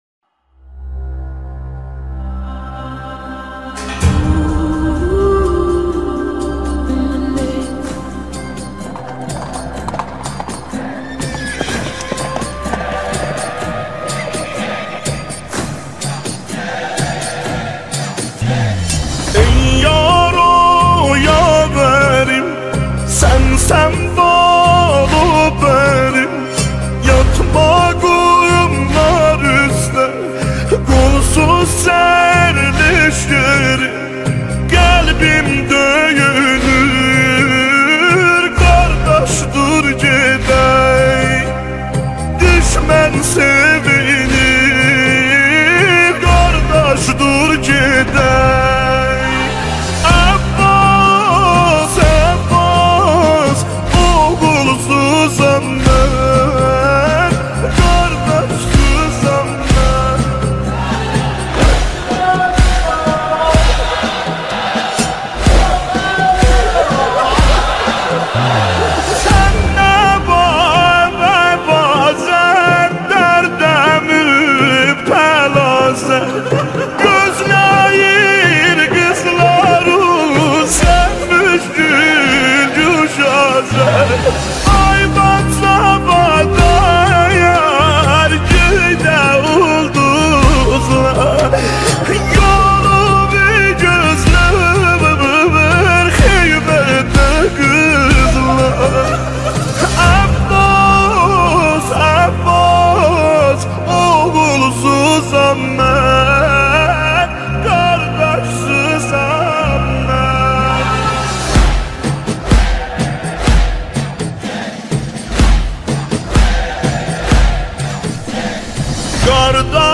مولودی حضرت ابوالفضل
مداحی آذری